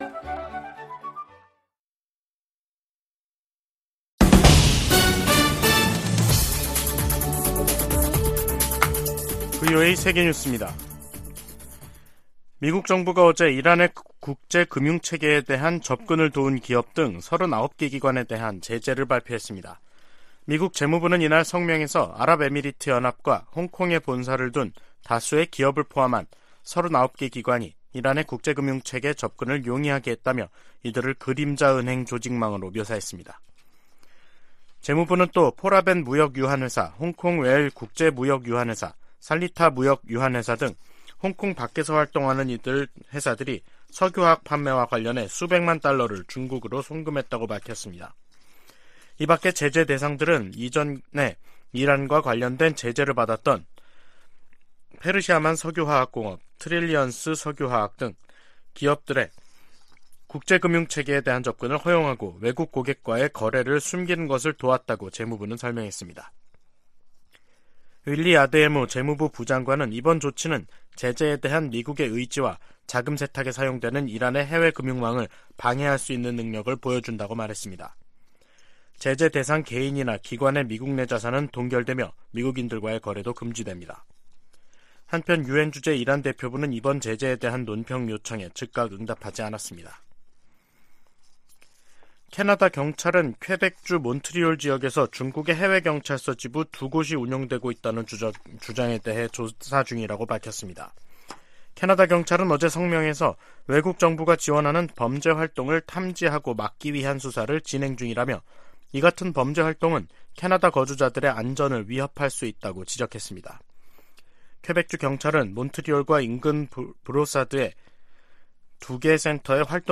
VOA 한국어 간판 뉴스 프로그램 '뉴스 투데이', 2023년 3월 10일 2부 방송입니다. 북한이 9일 신형 전술유도무기로 추정되는 탄도미사일을 최소 6발 서해로 발사했습니다. 미 국무부는 북한의 미사일 발사를 규탄하며, 대화에 열려 있지만 접근법을 바꾸지 않을 경우 더 큰 대가를 치르게 될 것이라고 경고했습니다. 미국 전략사령관이 의회 청문회에서 북한의 신형 대륙간탄도미사일로 안보 위협이 높아지고 있다고 말했습니다.